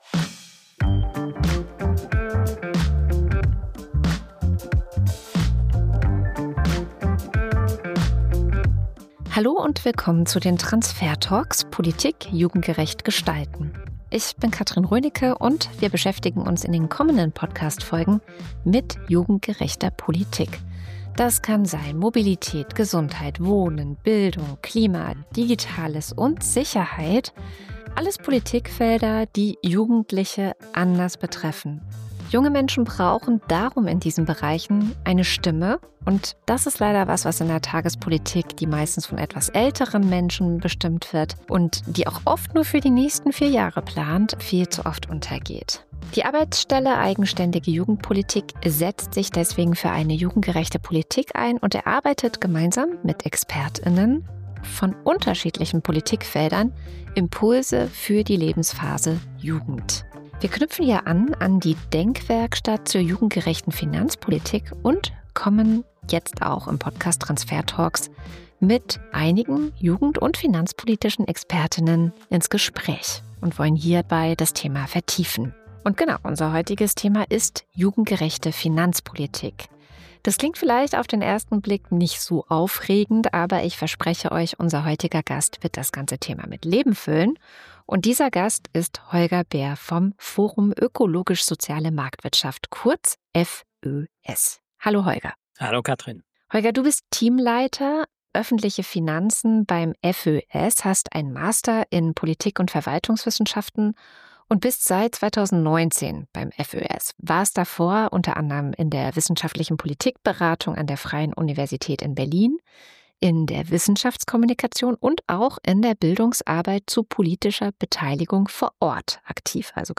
Es geht um zentrale Bausteine einer gerechten Finanzpolitik, von der Idee, „schlechte“ Dinge stärker zu besteuern, bis hin zur Rolle des Klima- und Transformationsfonds. Ein Gespräch darüber, wie gerechte Finanzpolitik heute aussieht und was sie für die Zukunft bedeutet.